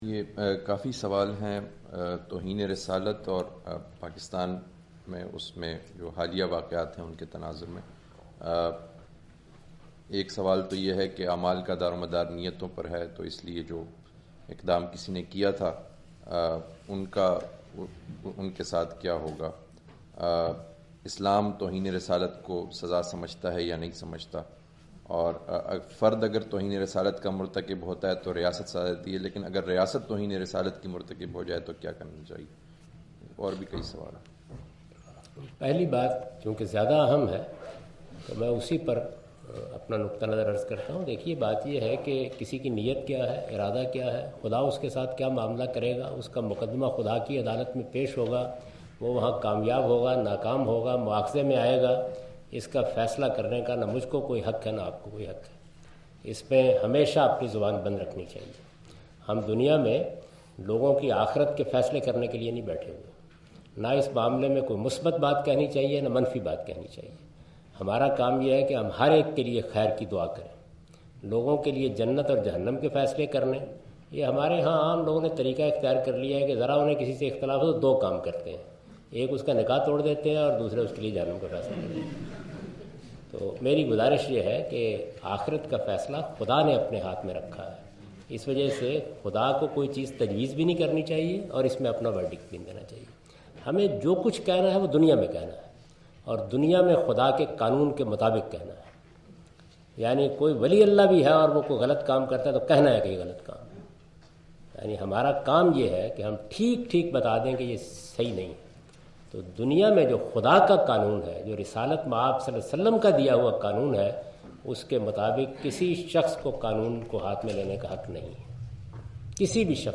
Category: English Subtitled / Questions_Answers /
Javed Ahmad Ghamidi answer the question about "punishment of blasphemy" during his visit to Queen Mary University of London UK in March 13, 2016.
جاوید احمد صاحب غامدی اپنے دورہ برطانیہ 2016 کےدوران کوئین میری یونیورسٹی اف لندن میں "توہین مذہب کی سزا" سے متعلق ایک سوال کا جواب دے رہے ہیں۔